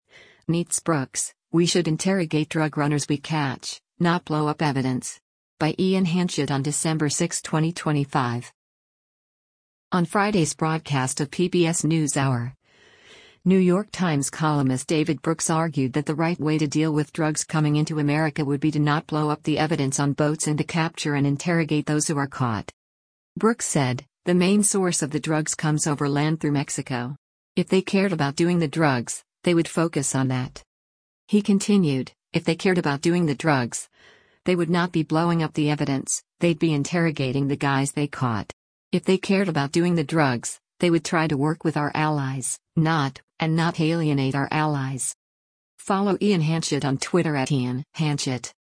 On Friday’s broadcast of “PBS NewsHour,” New York Times columnist David Brooks argued that the right way to deal with drugs coming into America would be to not blow up the evidence on boats and to capture and interrogate those who are caught.